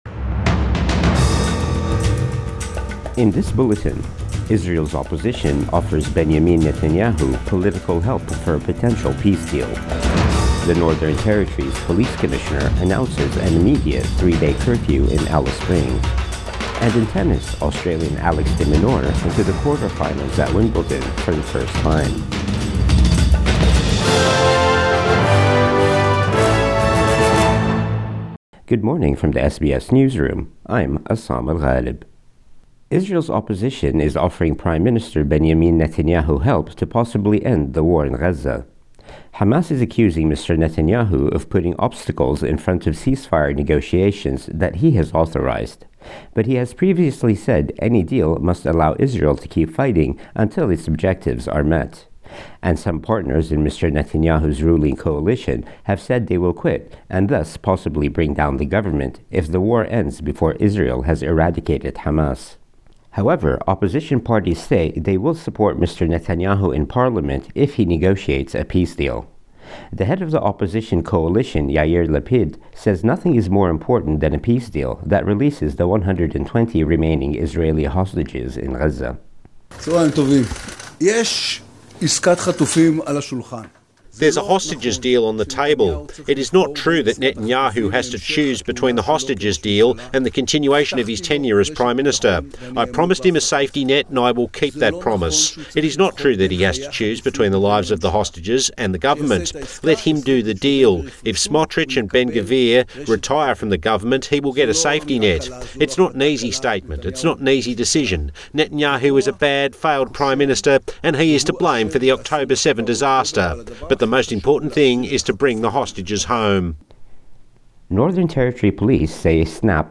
Morning News Bulletin 9 July 2024